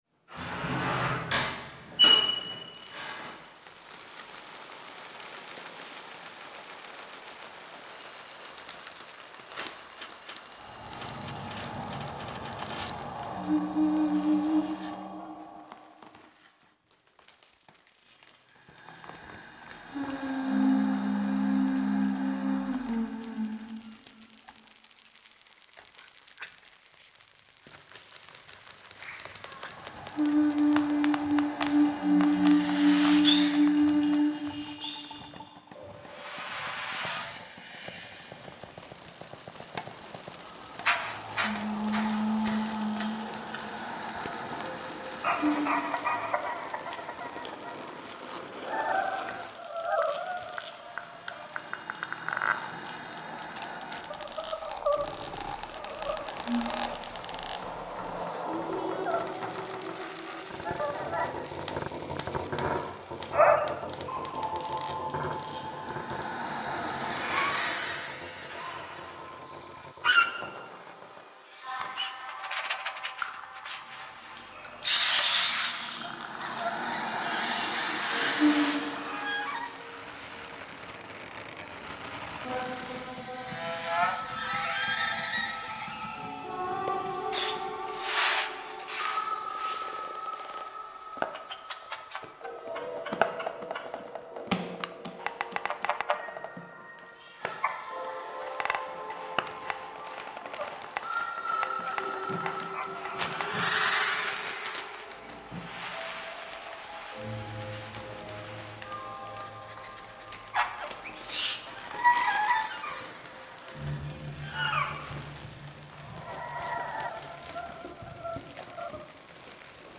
vocal improvvisations and co-arrangements.
composition and sound design.